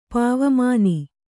♪ pāvamāni